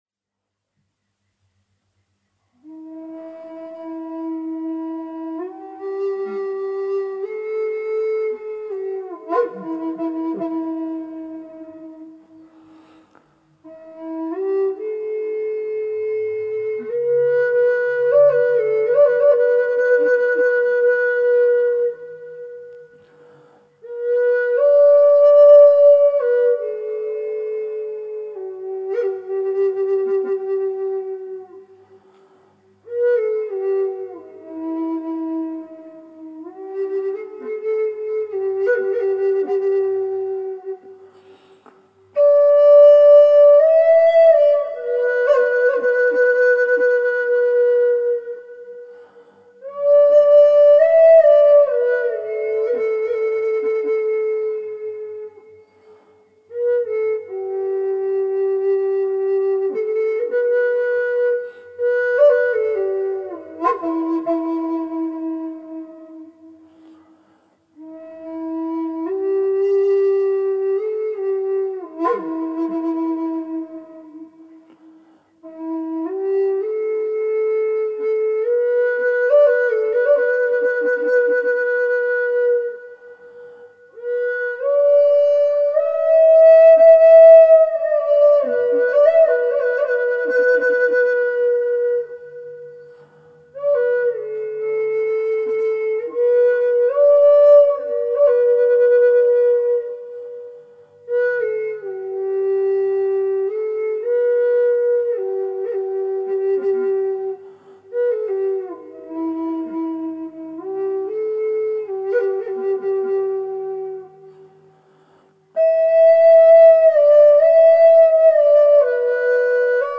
Free Guided Meditation Download
The 30-minute audio begins with a short flute introduction, and this beautiful meditative music helps to prepare you for the guided meditation.
holistic-meditation-audio